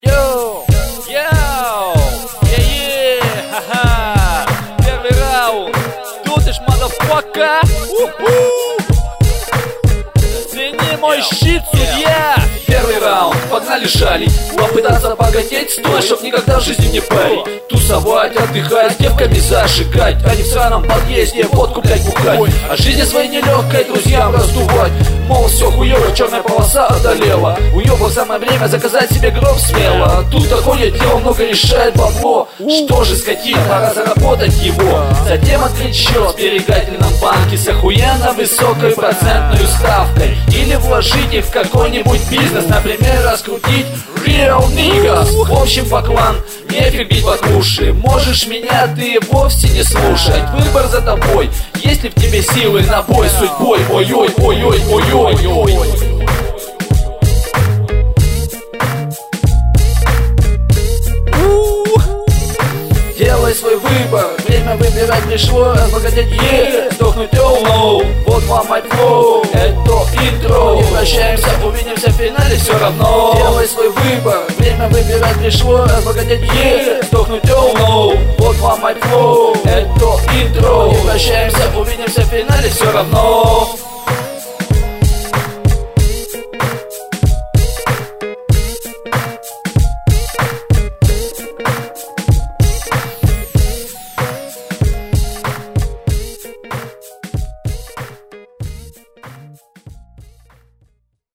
mp3,1584k] Рэп